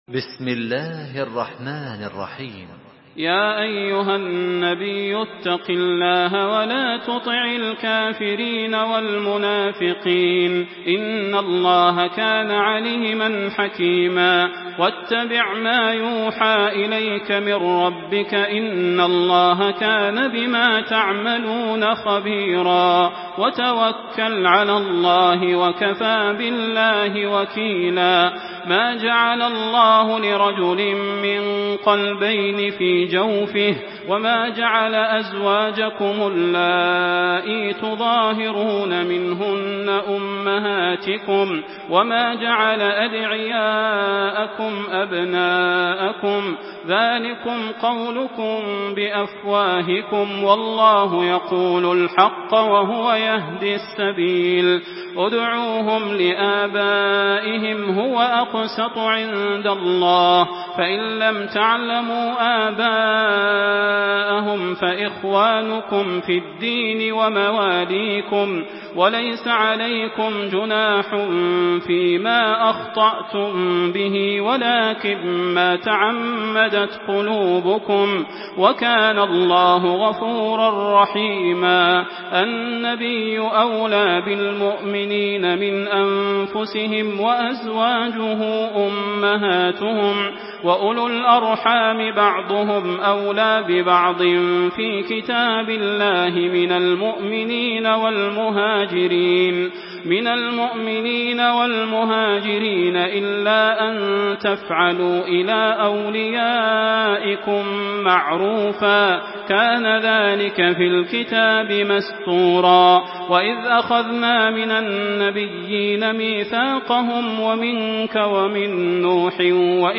Surah Al-Ahzab MP3 in the Voice of Makkah Taraweeh 1427 in Hafs Narration
Murattal